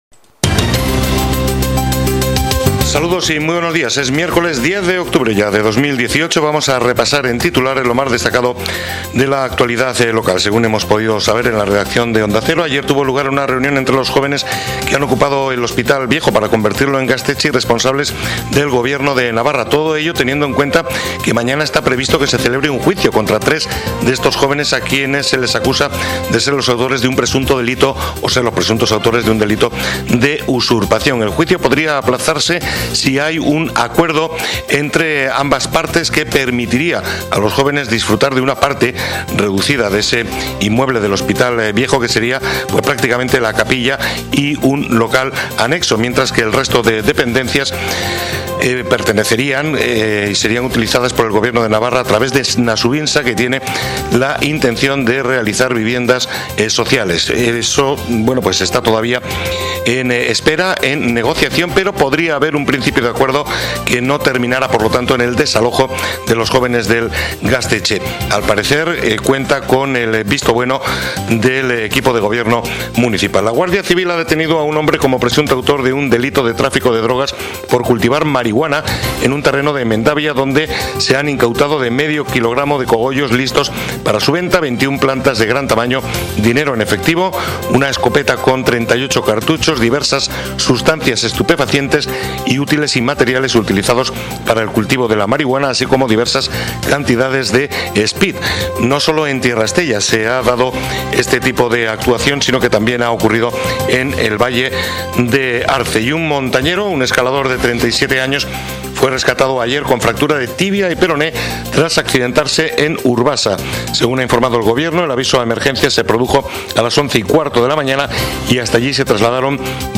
Titulares del día en Onda Cero Estella